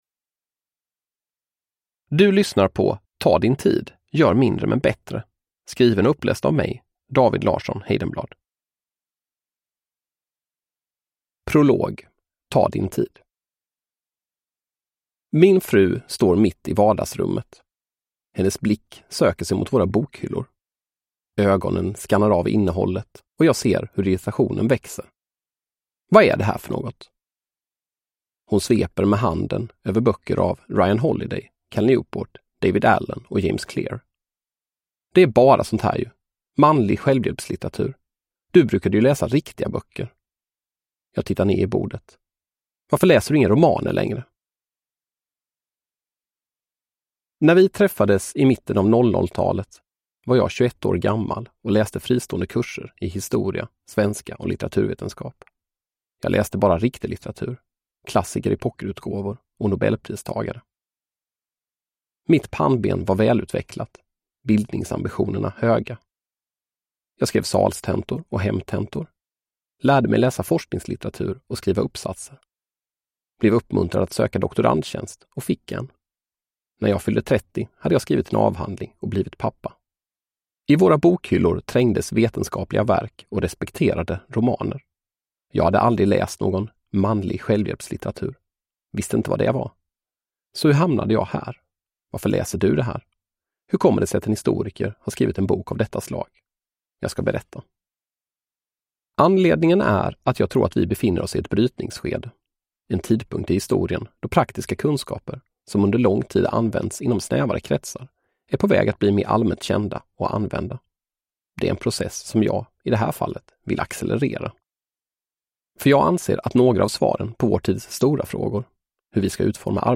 Ta din tid : gör mindre men bättre – Ljudbok